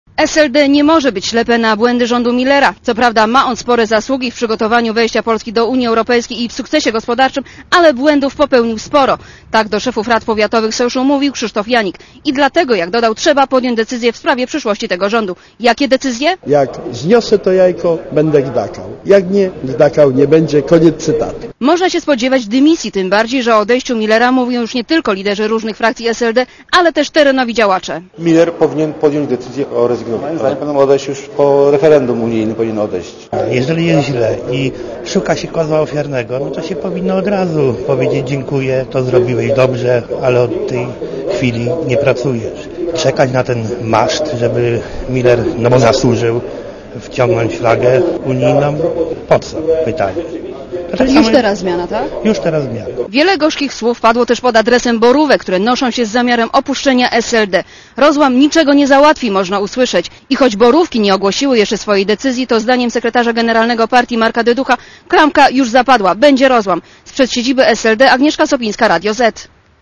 Posłuchaj relacji reporterki Radia Zet (227 KB)